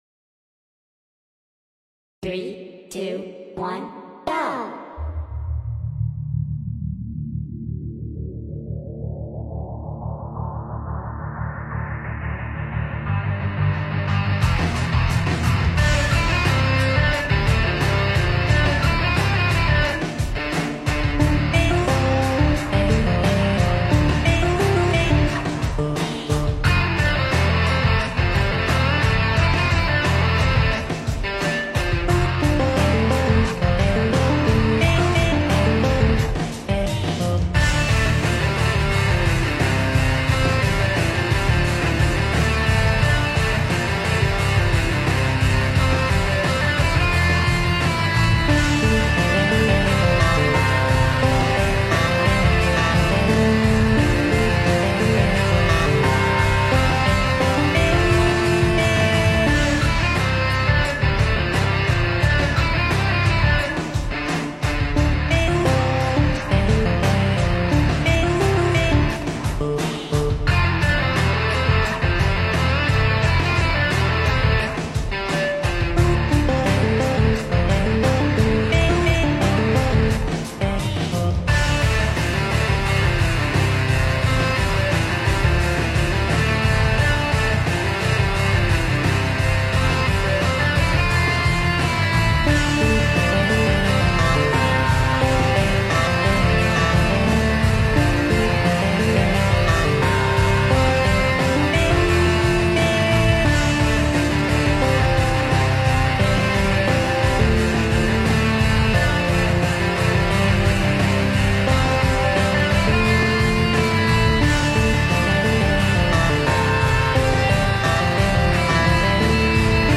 Slowed + Reverb